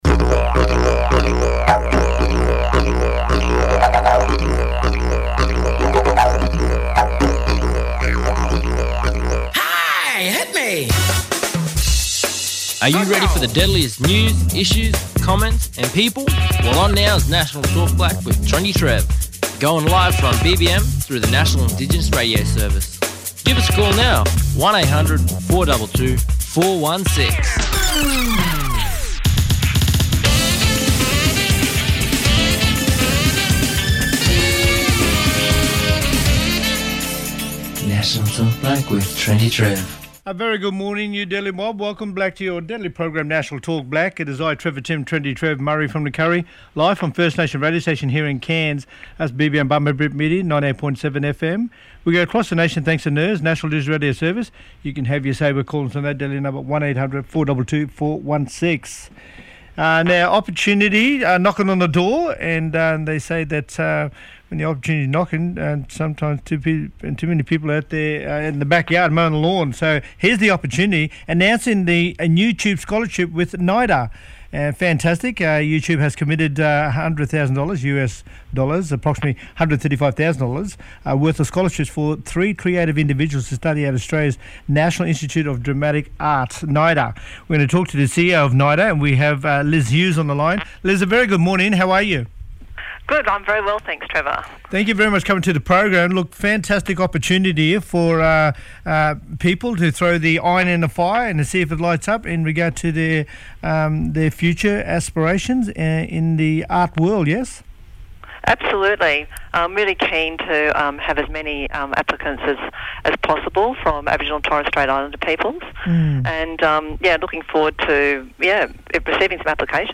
Jock Zonfrillo, The Orana Foundation Founder talking about the launch of their Indigenous Food Database. The Indigenous Food Database has seen 1,443 ingredients complied together for the first time from published literature on Indigenous uses, specific scientific knowledge, and species bioclimatic envelope information that has been outputted as maps on where to find them in Australia.